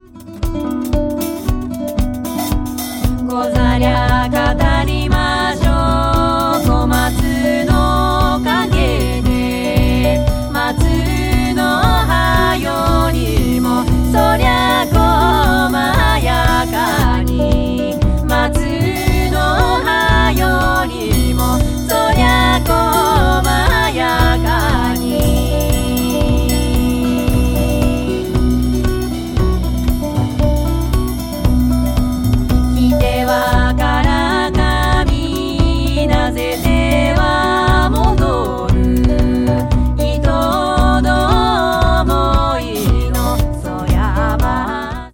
Dub Japanese World